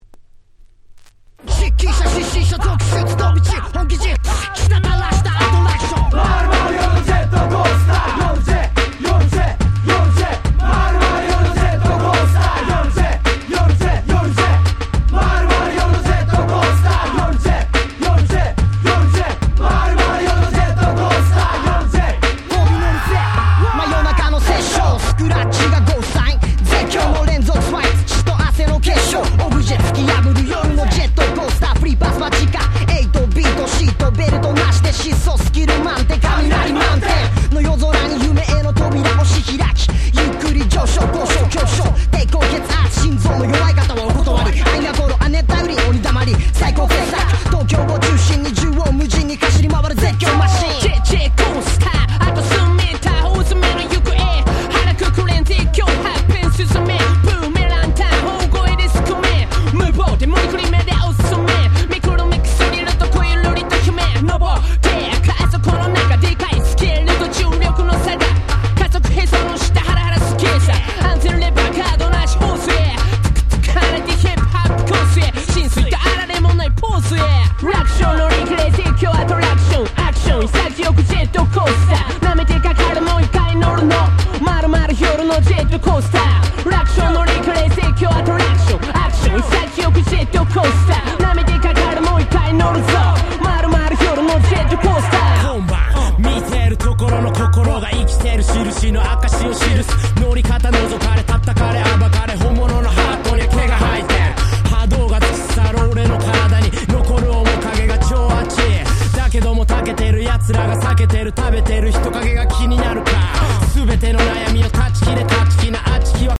97' Very Nice Japanese Hip Hop !!
90's J-Rap 日本語ラップ